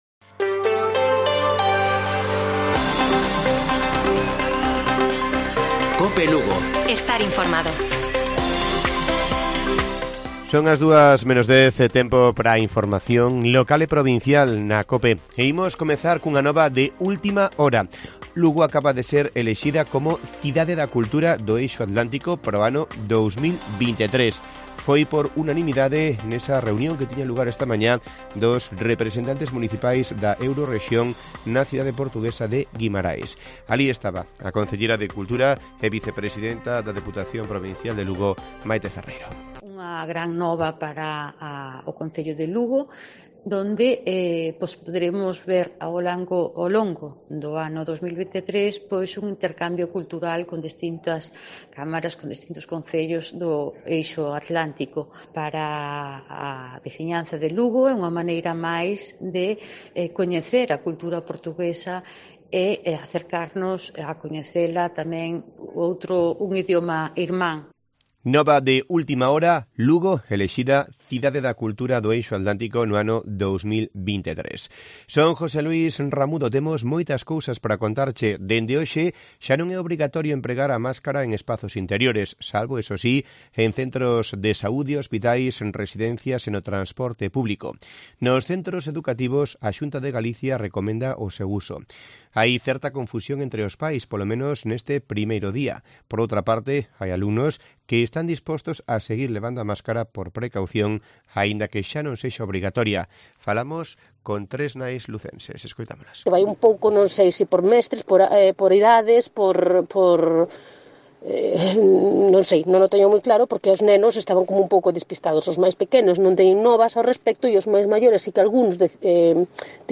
Informativo Mediodía de Cope Lugo. 20 de abril. 13:50 horas